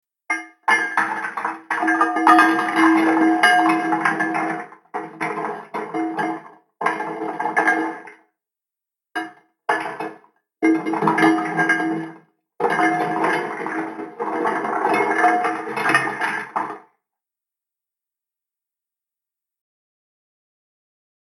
دانلود آهنگ قایق 5 از افکت صوتی حمل و نقل
جلوه های صوتی
دانلود صدای قایق 5 از ساعد نیوز با لینک مستقیم و کیفیت بالا